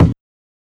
KICKMOBB.wav